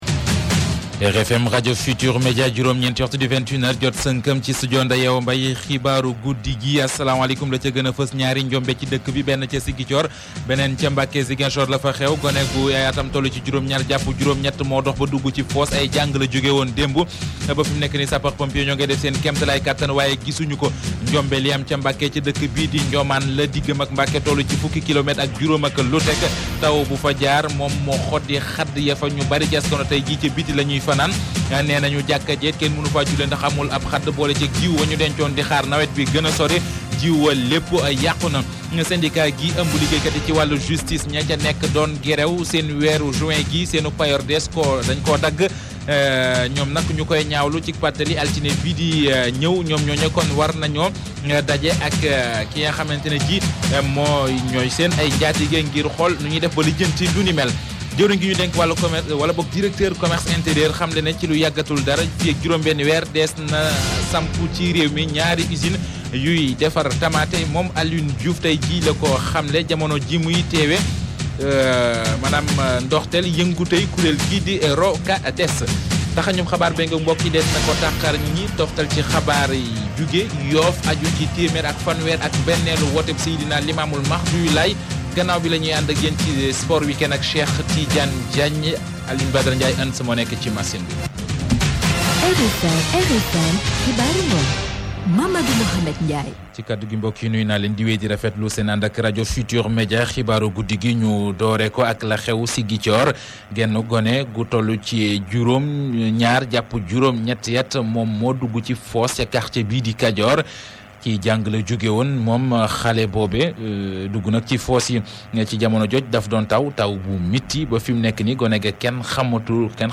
[ AUDIO ] Le journal parlé de la RFM ( 21 H - Wolof )